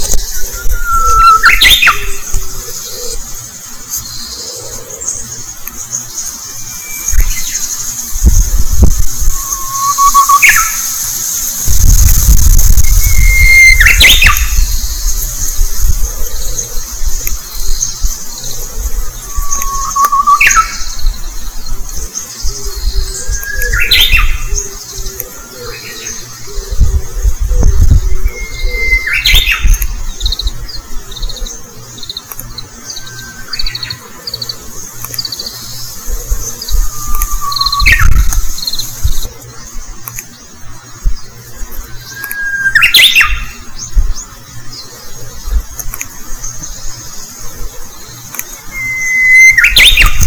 そして、この時期、前山を駆け回っていると、聞こえてくるのがウグイスの声です。
静かな所で一人で聞くウグイスの声。脈拍が上がって荒い息を抑えつつ、スマホでレコーディング。
雑音もありますが、聞いてみてください。
ウグイスの鳴き声.wav